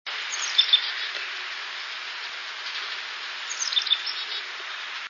Carolina Chickadee